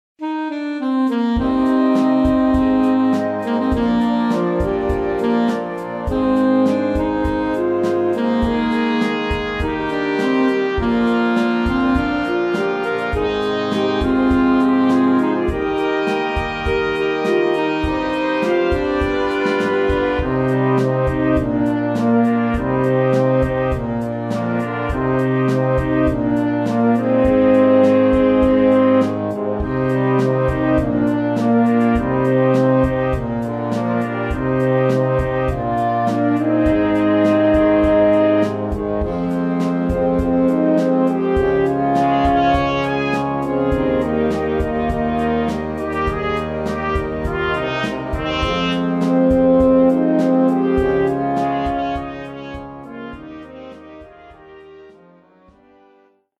dance-pop
eurodance